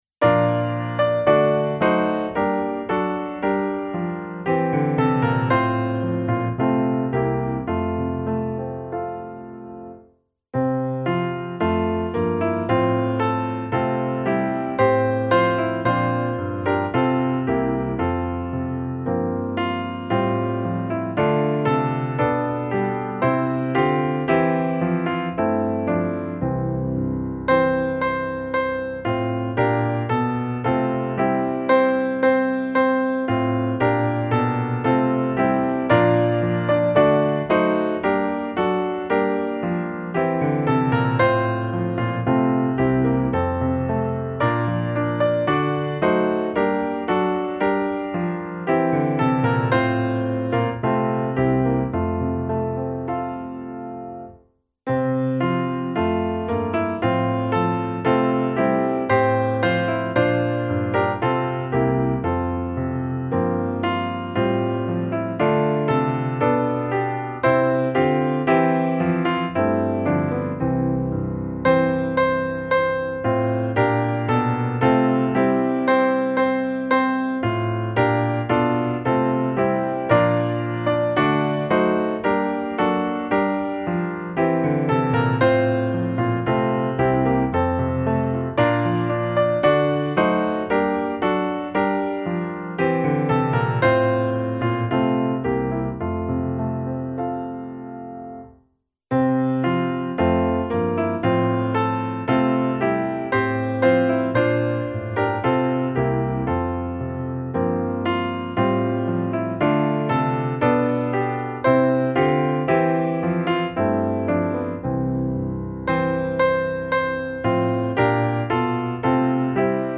Lyss till änglasångens ord - musikbakgrund
Gemensam sång
Musikbakgrund Psalm